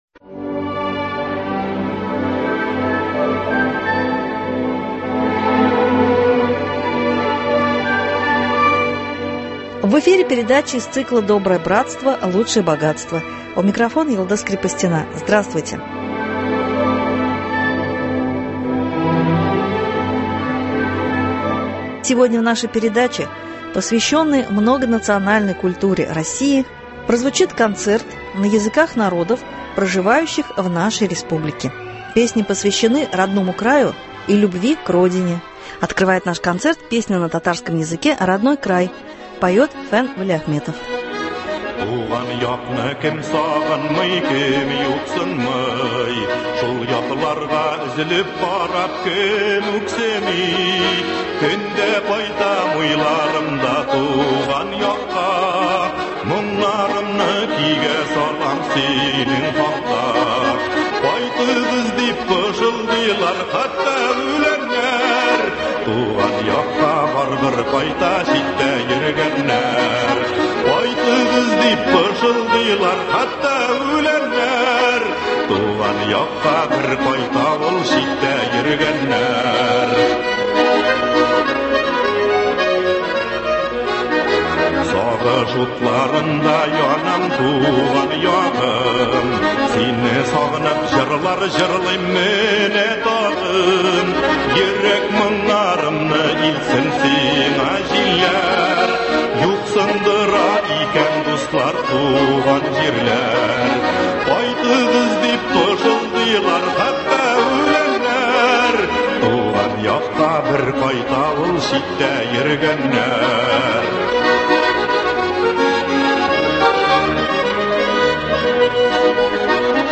В преддверии Дня Республики Татарстан звучит концерт на языках народов Поволжья. Песни о Родине.